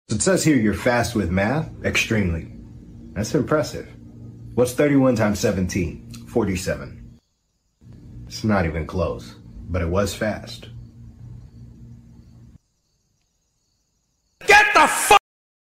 funny animation interview (animation meme)